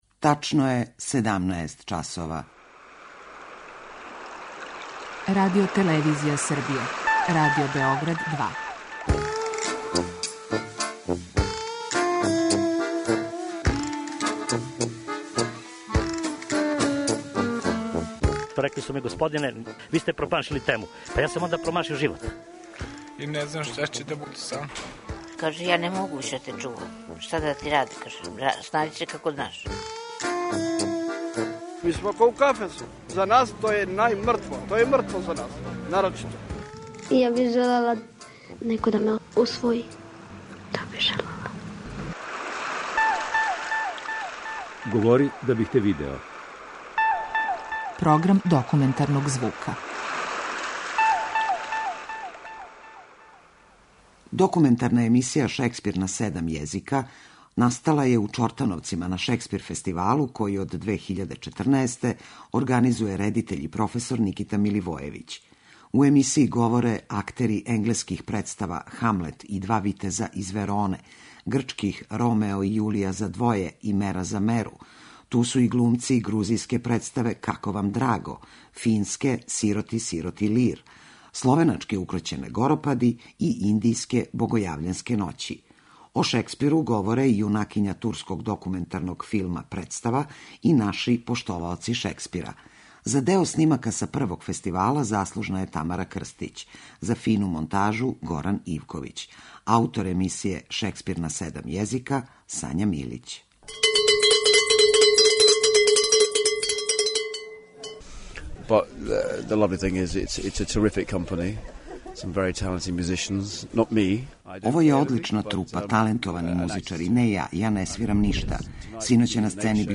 Лепота глумачког говора на енглеском, руском, грчком, турском, финском, словеначком и српском - идеја су документарне емисије „Шекспир на седам језика”.
Неки од саговорника користе матерњи језик, други се испомажу својом специфичном варијантом енглеског или руског.